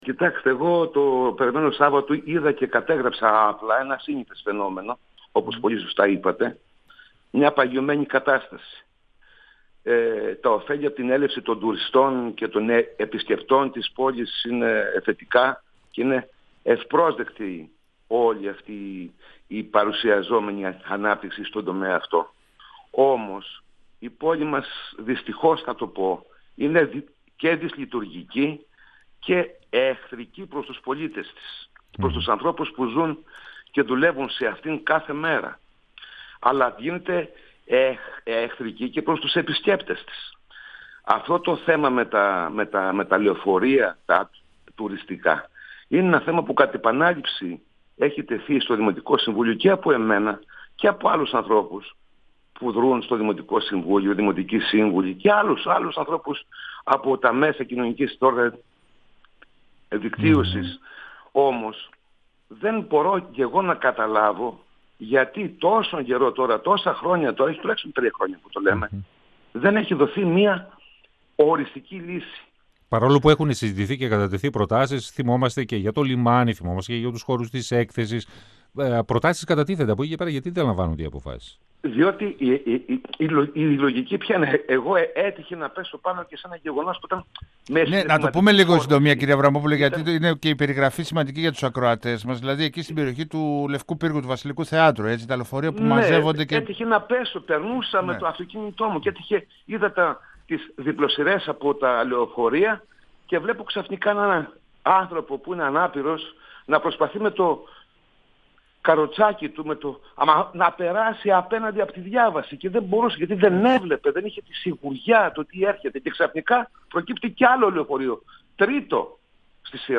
Ο δημοτικός σύμβουλος και πρώην πρόεδρος του δημοτικού συμβουλίου Θεσσαλονίκης, Παναγιώτης Αβραμόπουλος, στον 102FM του Ρ.Σ.Μ. της ΕΡΤ3
Συνέντευξη